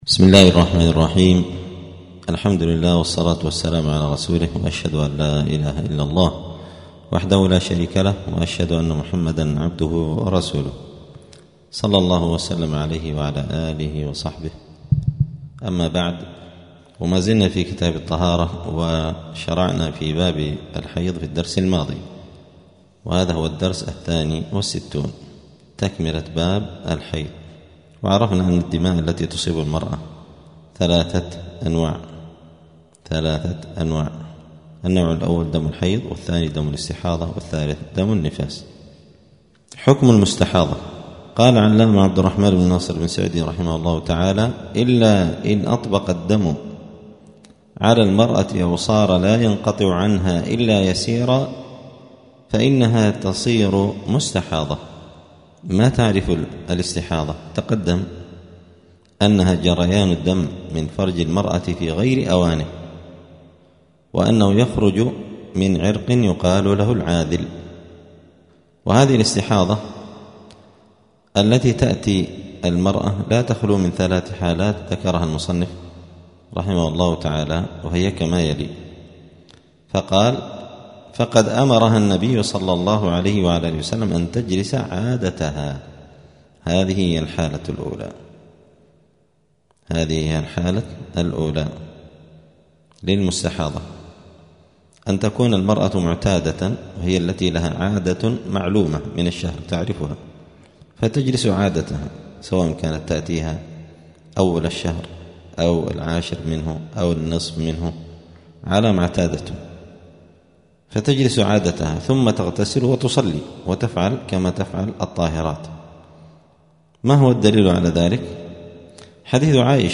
*الدرس الثاني والستون (62) {كتاب الطهارة تكملة باب الحيض}*